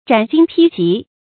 斩荆披棘 zhǎn jīng pī jí
斩荆披棘发音
成语注音 ㄓㄢˇ ㄐㄧㄥ ㄆㄧ ㄐㄧˊ